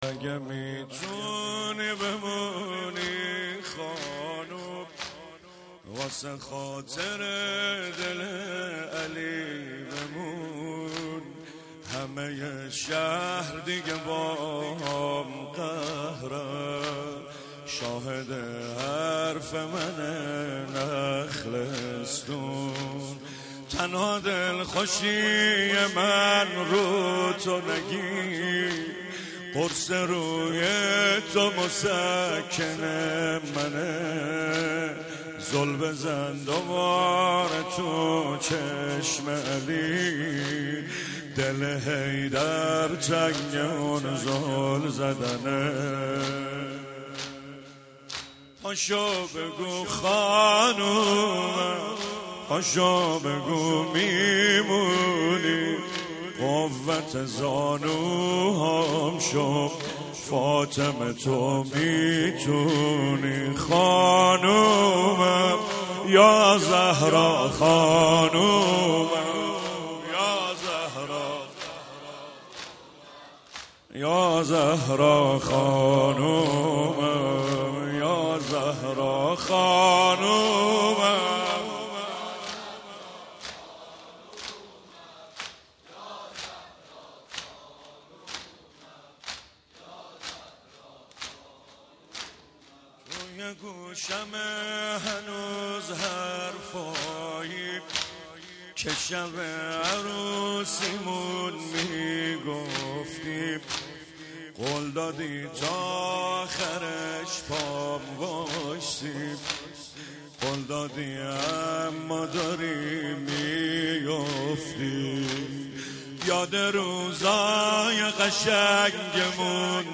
مداحی فاطمیه